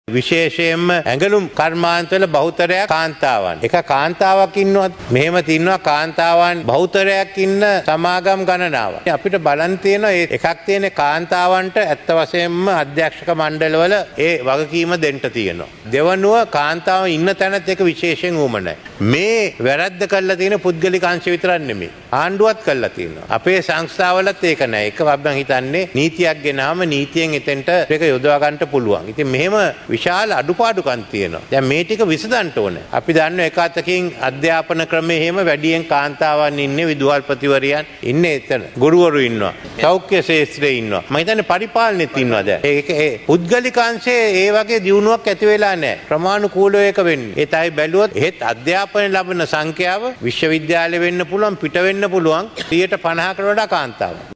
ජනගහනයෙන් 52 % ක් රට තුළ කාන්තා නියෝජනයක් පැවතීම හේතුවෙන්ප රිපාලන කටයුතුවලදී එම ශ්‍රමදායකත්වය ලබාගත යුතු බවටයි ජනාධිපතිවරයා පාර්ලිමේන්තුවේදී අවධාරණය කළේ.
මේ එහිදී වැඩිදුරටත් අදහස් දැක් වූ ජනාධිපති රනිල් වික්‍රමසිංහ මහතා.